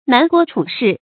注音：ㄣㄢˊ ㄍㄨㄛ ㄔㄨˇ ㄕㄧˋ
南郭處士的讀法